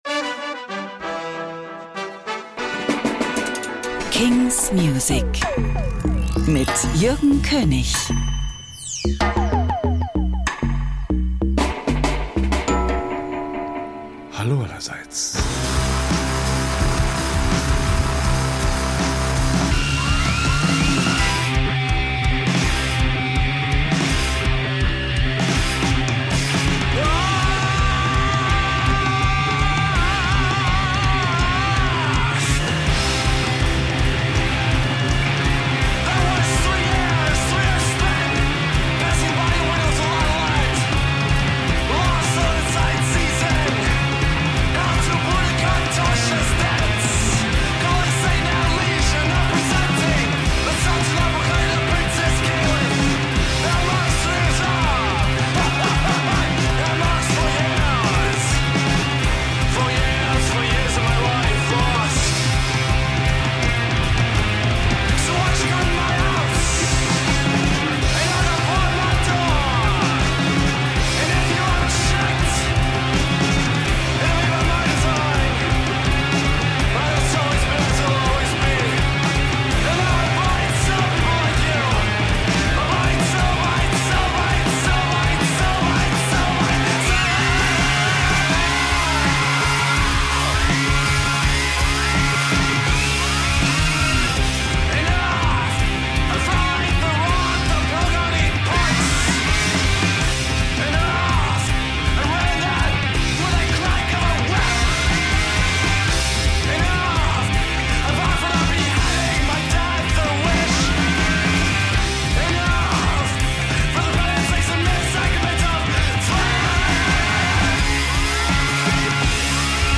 selection of brandnew indie & alternative releases